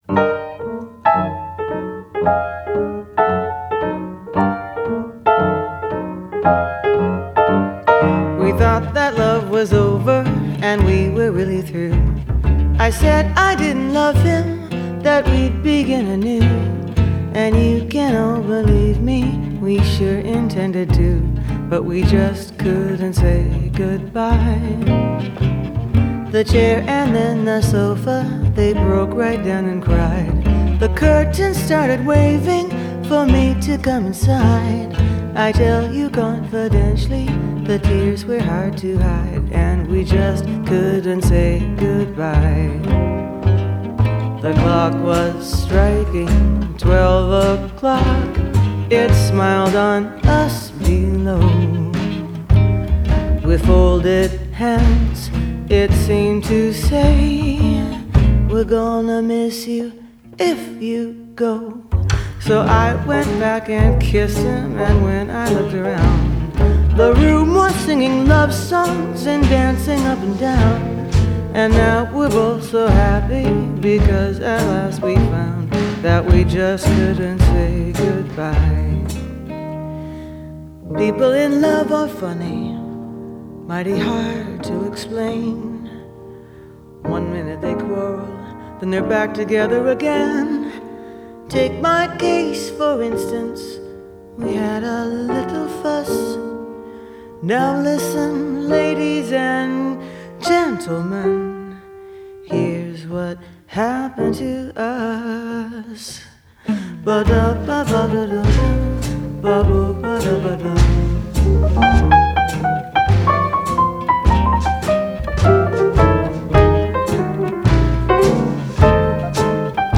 Genre: Jazz, Vocal Jazz